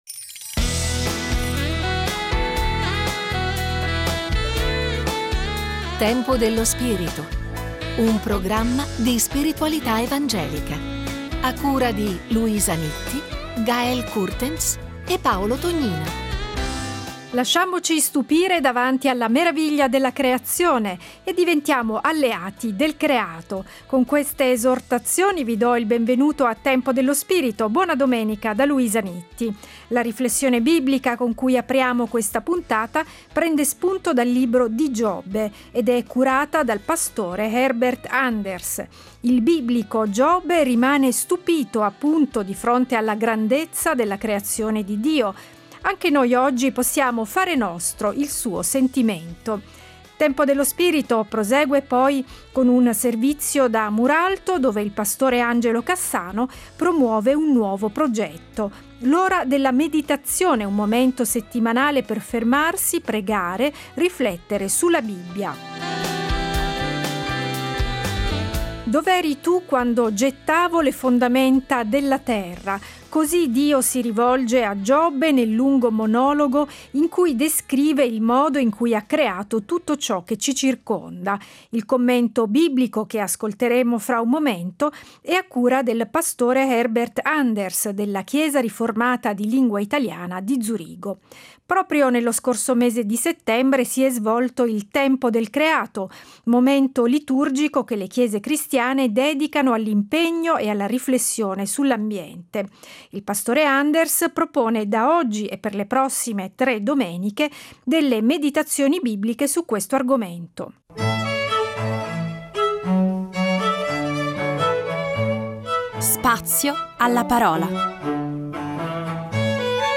Scopri la serie Tempo dello spirito Settimanale di spiritualità evangelica.